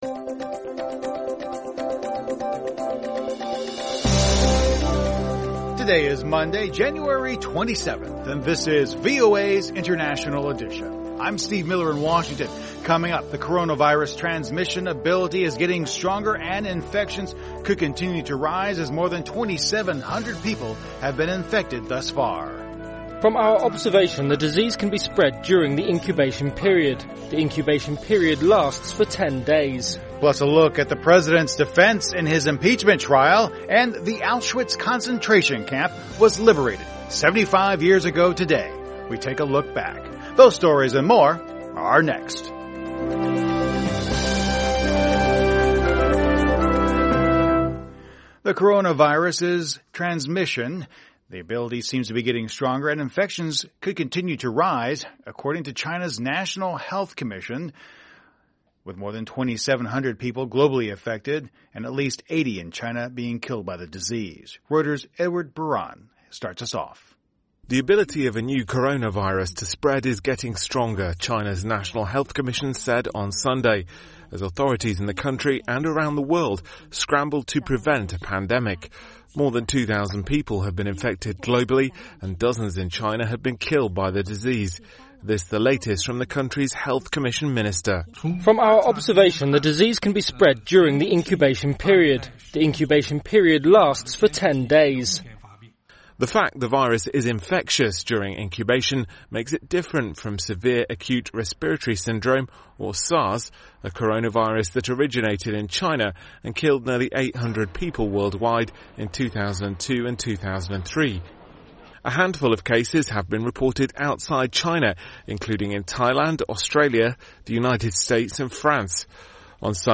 The coronavirus transmission ability is getting stronger and infections could continue to rise, China's National Health Commission said on Sunday, with more than 2,000 people globally infected and at least 56 in China killed by the disease. VOA speaks to an American who has been living in Wuhan, where the virus is believed to have originated, about her experience.